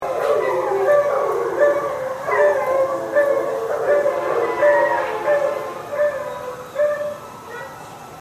Whenever there is a siren in the neighborhood, it is followed by the howling of dogs.
They add a deep, consistent tone to the overall melody.
It’s high-pitched voice adds harmony to the mix.
And this howling sounds like something that you’d hear in a werewolf movie.
dogsbarking1.mp3